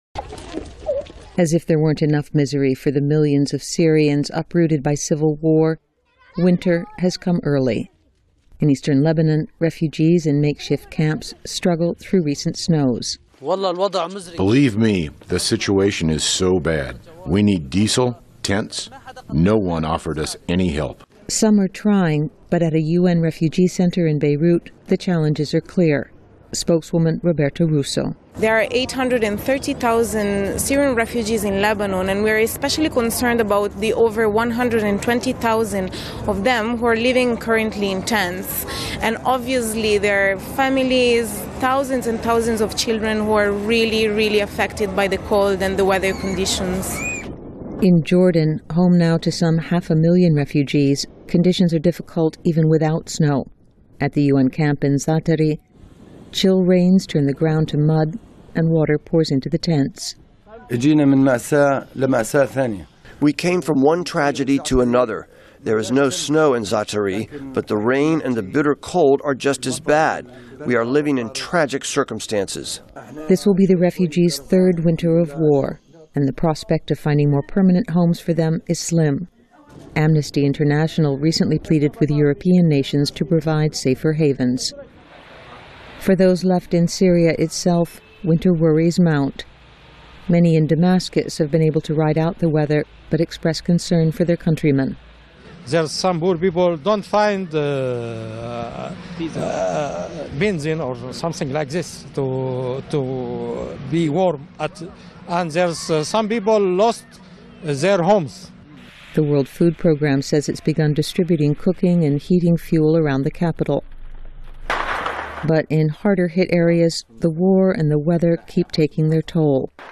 VOA常速英语|冬季风暴提前到来 叙利亚难民处境恶劣(VOA视频)